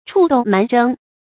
触斗蛮争 chù dòu mán zhēng 成语解释 触和蛮。